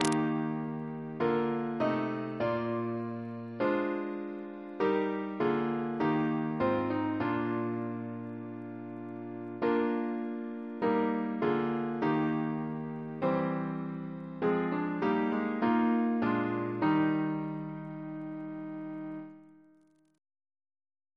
Double chant in E♭ Composer: Sir George Elvey (1816-1893), Organist of St. George's Windsor; Stephen's brother Reference psalters: ACB: 231; ACP: 275; RSCM: 48